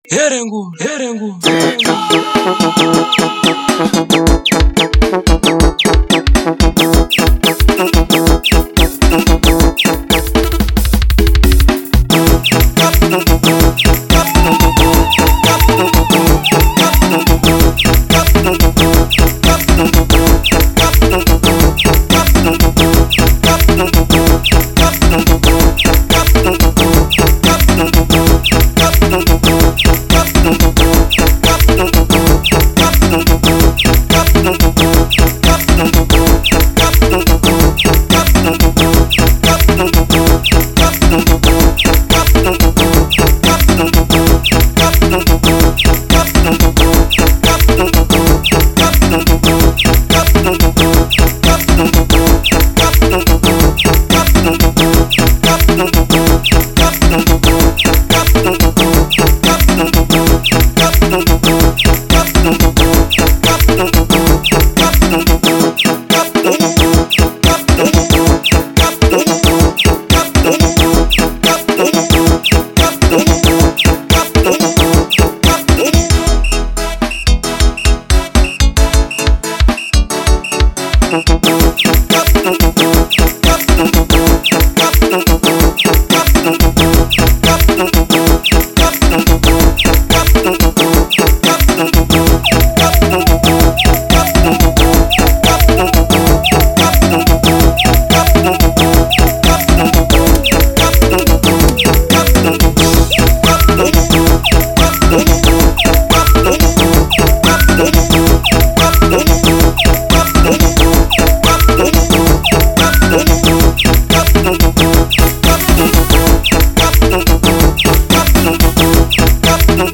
04:57 Genre : Xitsonga Size